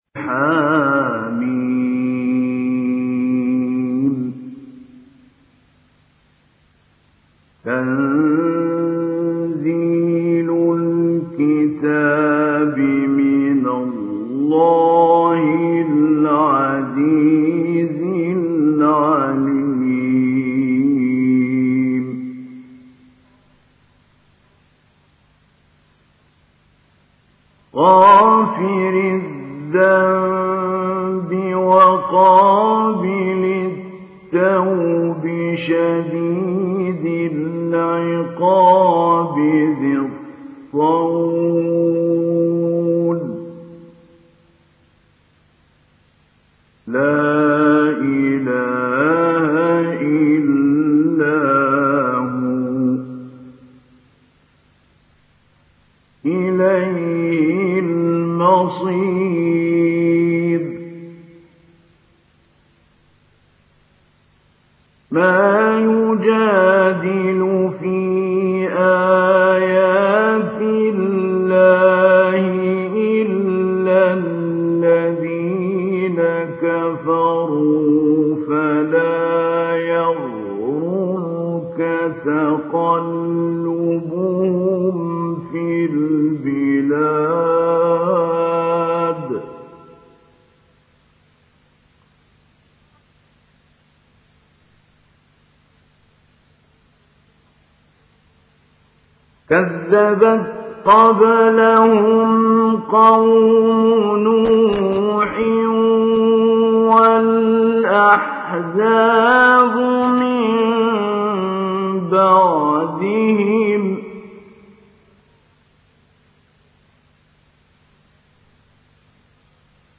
Surah Ghafir Download mp3 Mahmoud Ali Albanna Mujawwad Riwayat Hafs from Asim, Download Quran and listen mp3 full direct links
Download Surah Ghafir Mahmoud Ali Albanna Mujawwad